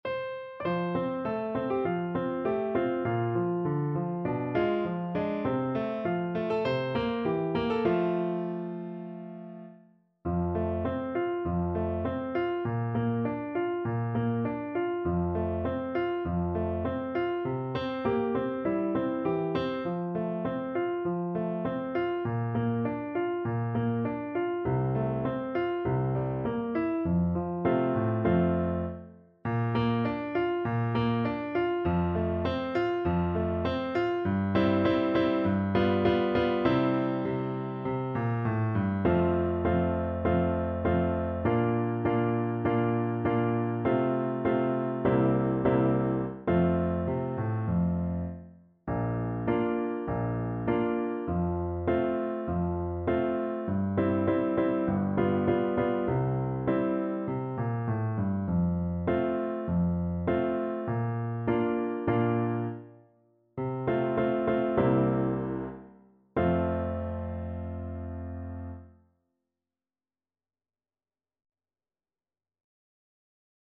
4/4 (View more 4/4 Music)
F major (Sounding Pitch) (View more F major Music for Voice )
~ = 100 Moderato
Traditional (View more Traditional Voice Music)